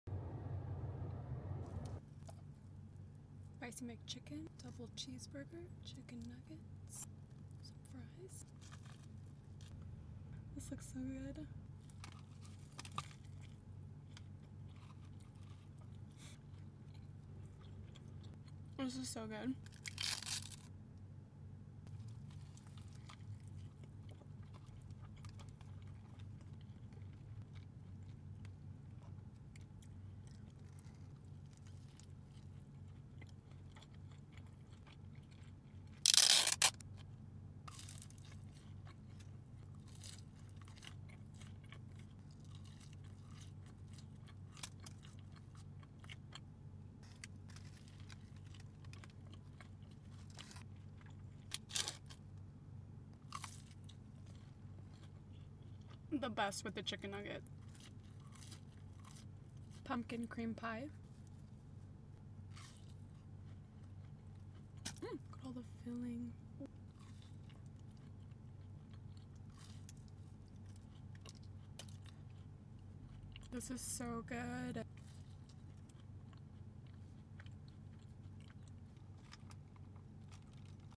McDonald’s Mukbang! Spicy mcchicken , sound effects free download